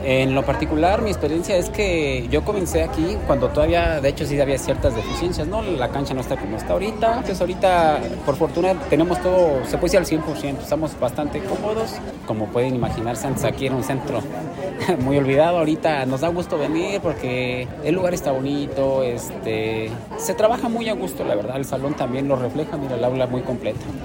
AudioBoletines
Lorena Alfaro García, Presidenta de Irapuato